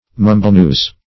Mumblenews \Mum"ble*news`\, n.